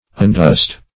Undust \Un*dust"\